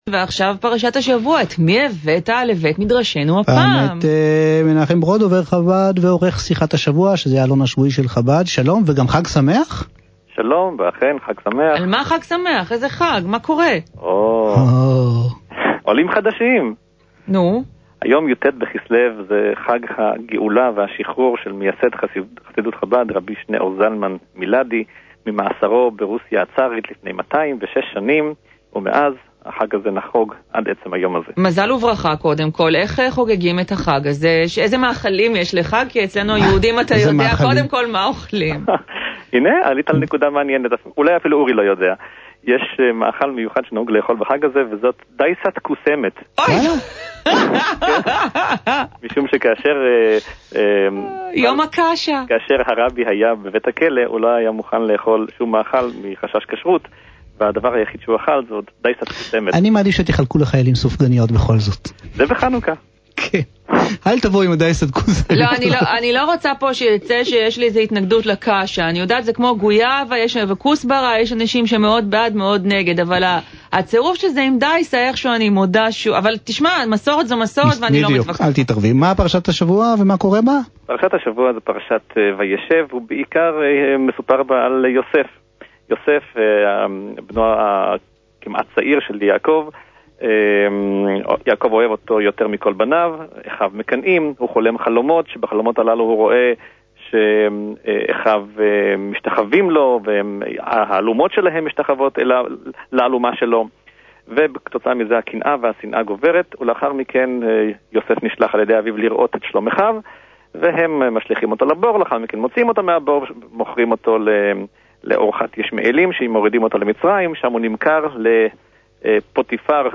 בתכנית "המילה האחרונה" המשודרת בגלי-צה"ל, בהנחיית אורי אורבך ועירית לינור. בתחילת השיחה שוחחו קצת על חג-הגאולה י"ט כסלו (ואפילו על מאכל החג - "שווארצע קאשע"),